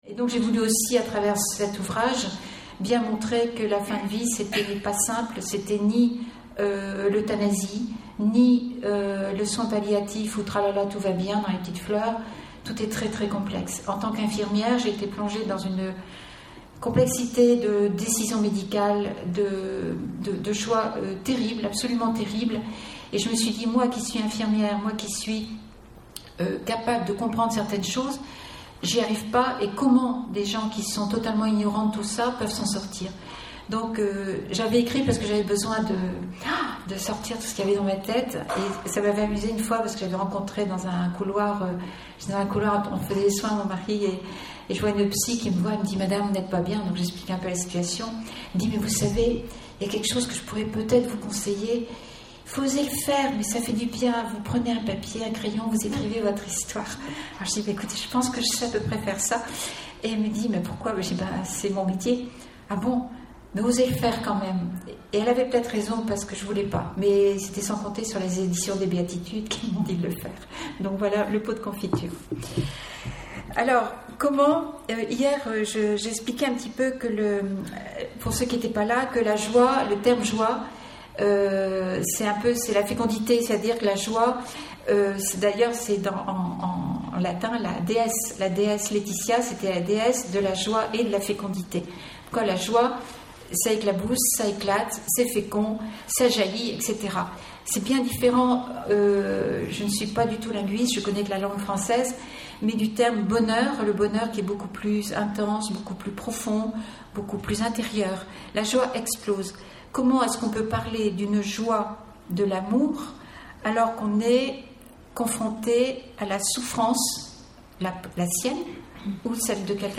Conférence donnée lors de la session de la Communauté des Béatitudes à Lisieux en août 2017.